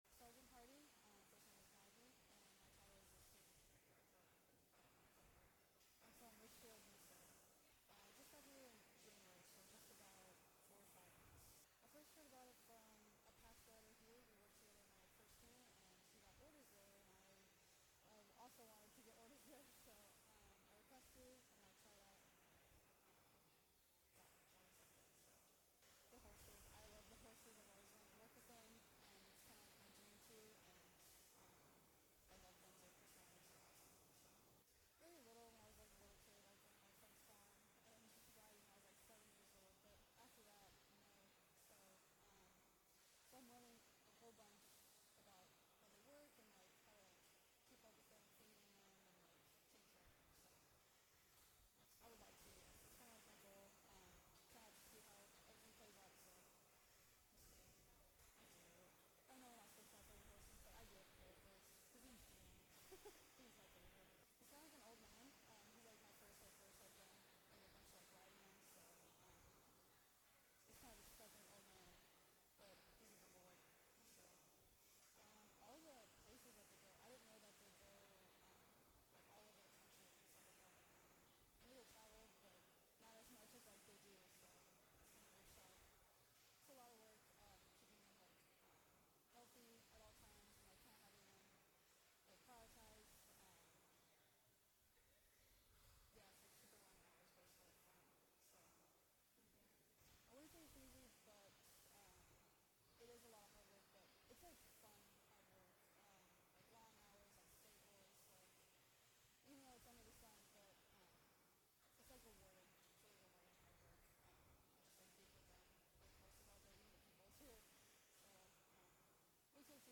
conducts an interview at Iron Hill Ranch in Union, Missouri on May 13, 2024. MCG conducted a cross-country journey from Marine Corps Logistics Base Barstow, California to the National Capital Region for the first time in over a decade to participate in Preakness, the National Memorial Day Parade and a series of additional events designed to uphold the Marine Corps’ prominence, promote the Marine Corps’ only Mounted Color Guard asset and support recruiting nationwide.